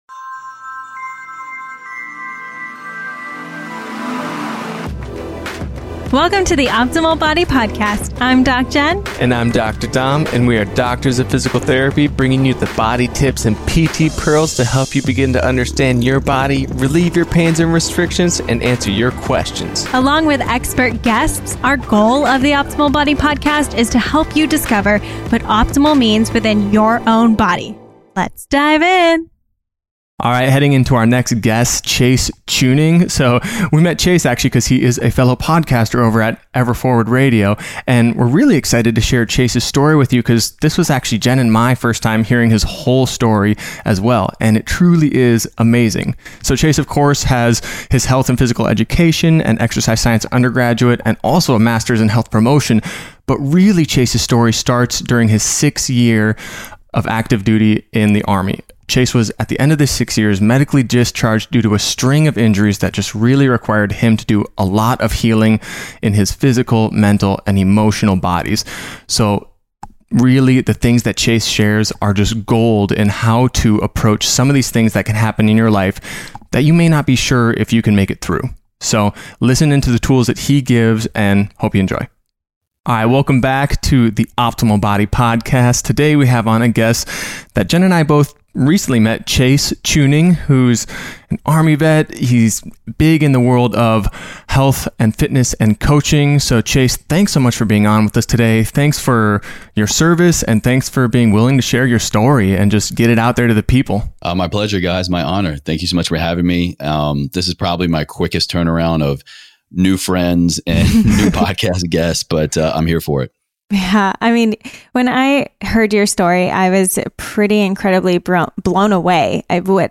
Join us as we interview